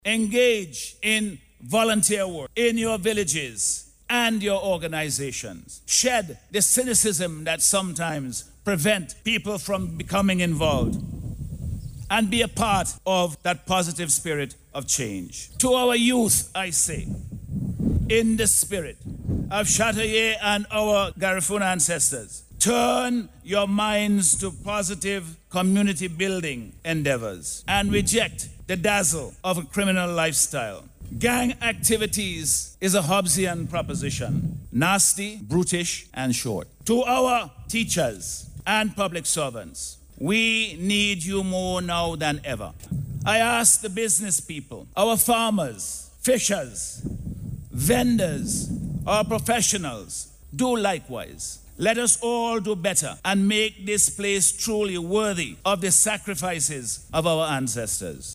Dr. Friday made the call during his address at the Annual Wreath-Laying Ceremony, held on Saturday at the Obelisk at Dorsetshire Hill, in honour of this country’s National Hero, Joseph Chatoyer.